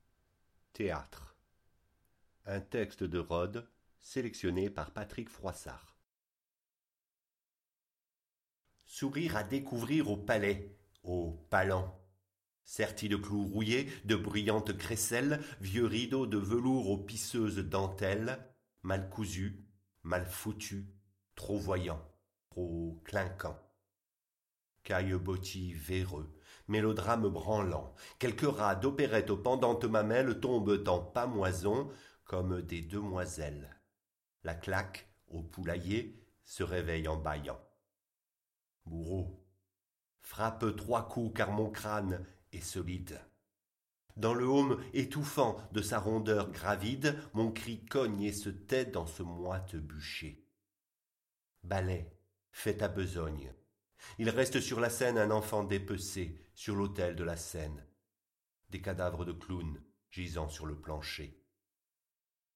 Des alexandrins criants et forts repris par la voix expressive